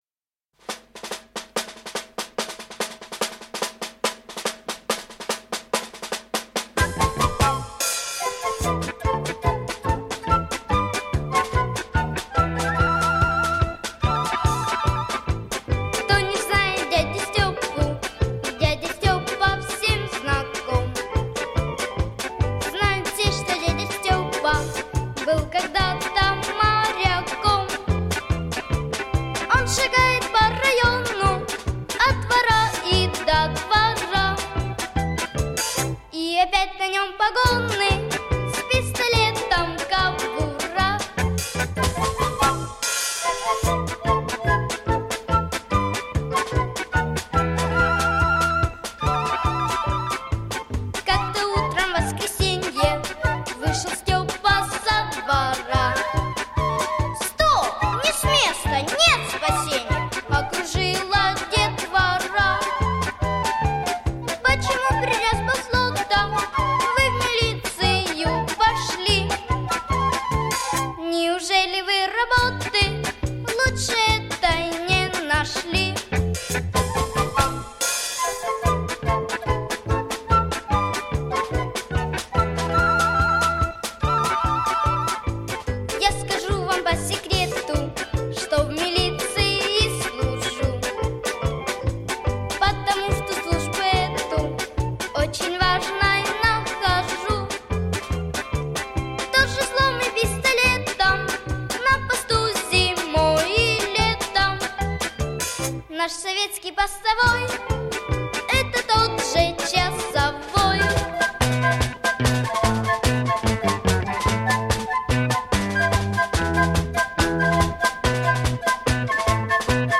• Категория: Детские песни
советские детские песни